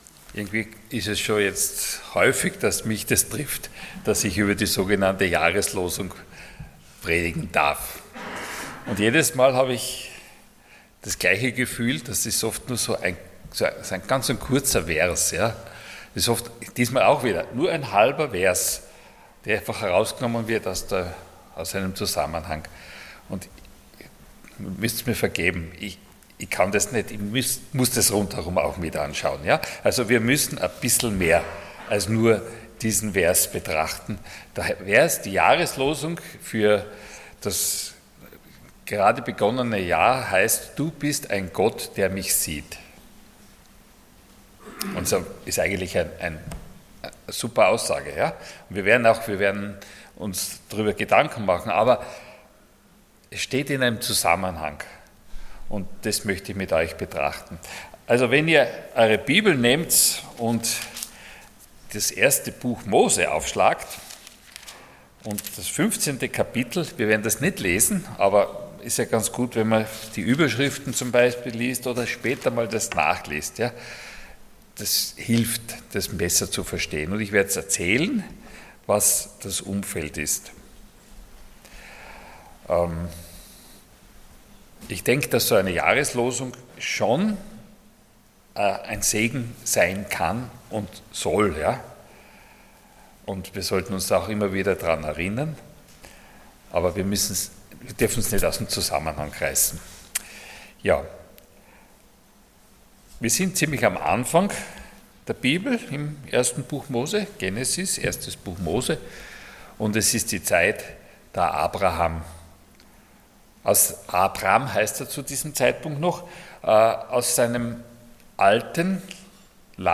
Passage: Genesis 16:13 Dienstart: Sonntag Morgen